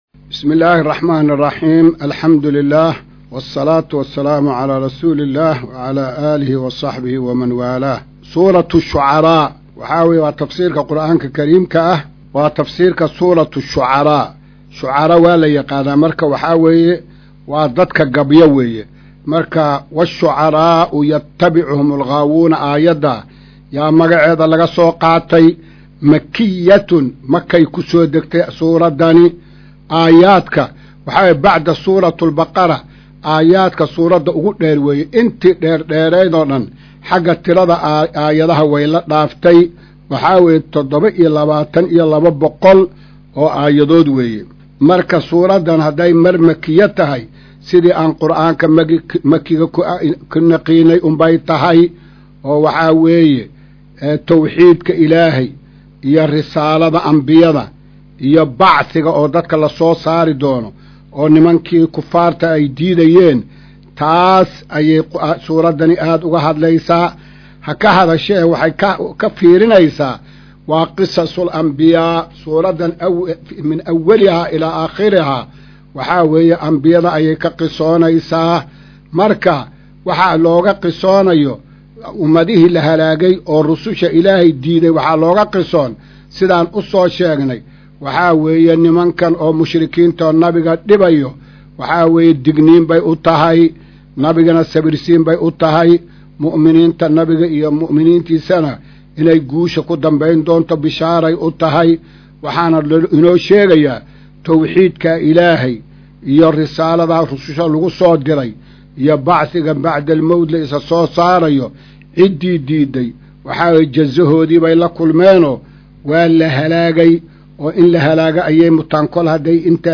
Maqal:- Casharka Tafsiirka Qur’aanka Idaacadda Himilo “Darsiga 177aad”